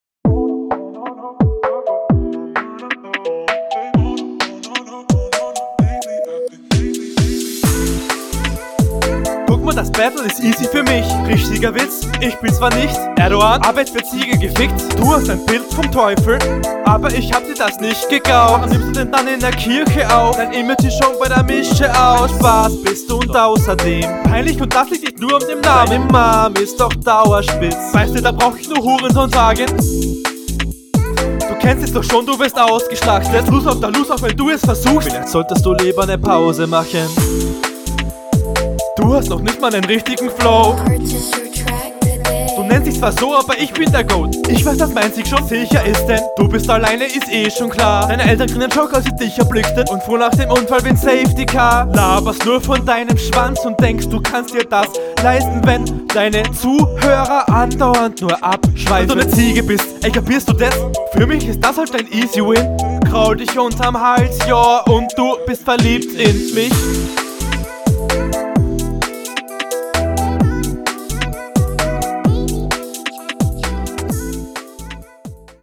crazy beat pick fürs battle, mag ich aber! erdogan line ist ok. die stimm melodien …
Oh Partybeat oder was. Du bist leider etwas zu leise wodurch vieles verloren geht.